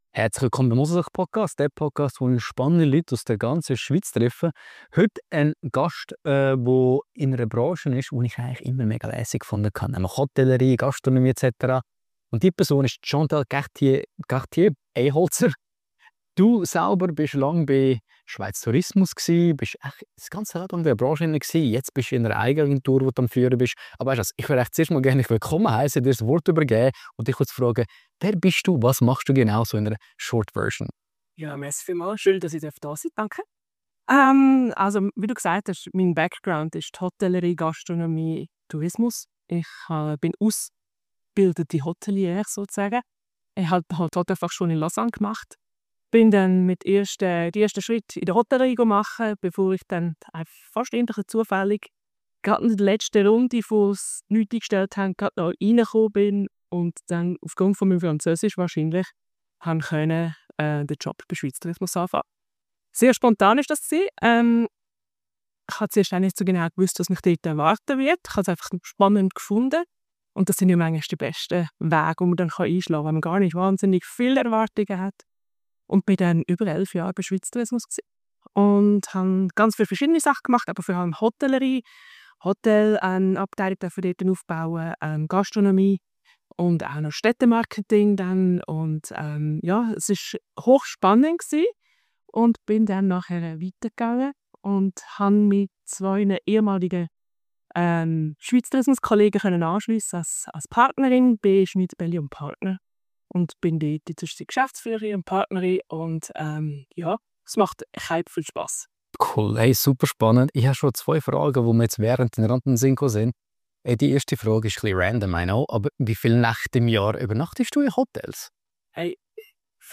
Wir reden über die grössten Nachhaltigkeitsprobleme der Branche, was Ecuador und Costa Rica mit Schweizer Gästen verbindet und wie man eine Region erfolgreich vermarktet. Ein ehrliches Gespräch über Tourismus, Verantwortung und die Kunst, mit Leidenschaft für Nachhaltigkeit zu arbeiten.